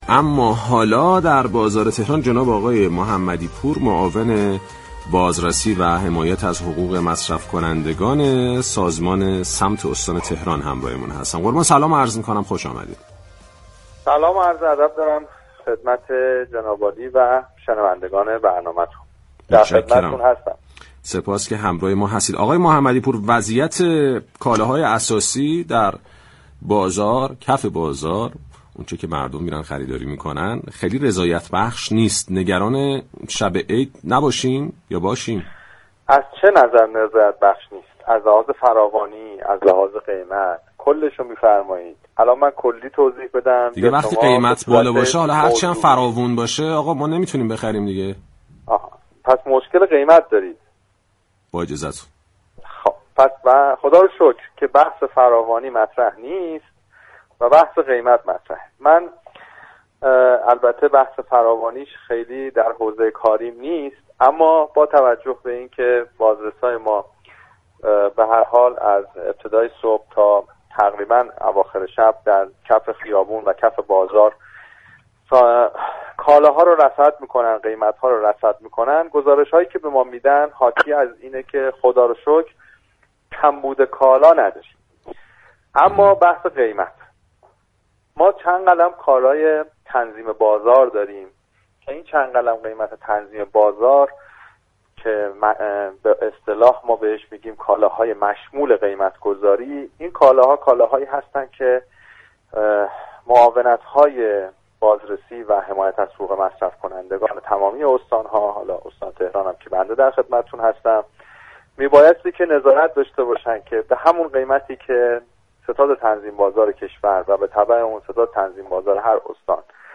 به گزارش پایگاه اطلاع رسانی رادیو تهران، معاون بازرسی و حمایت از حقوق مصرف كنندگان وزارت صمت در گفتگو با برنامه بازار تهران با اشاره به اینكه بازرسان وزارت صمت از صبح تا پاسی از شب در حال رصد بازار هستند گفت: خدا رو شكر كمبود كالا نداریم .